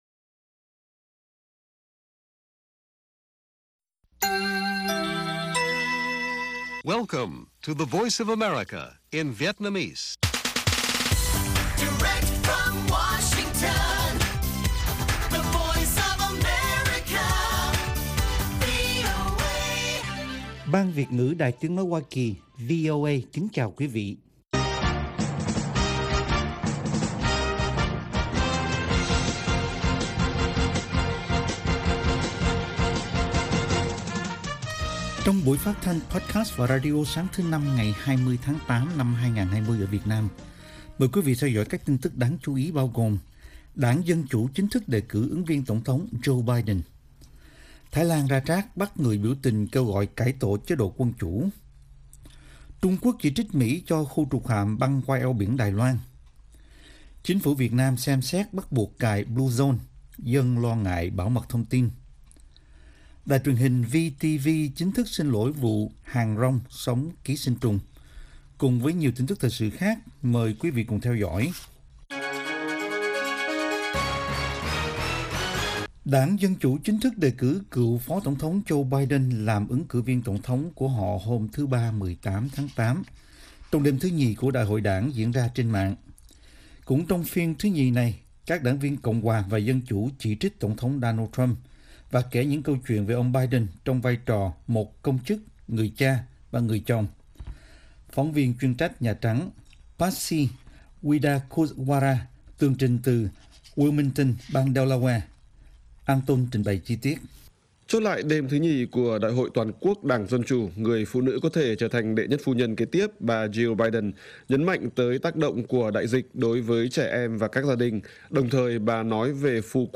Bản tin VOA ngày 20/8/2020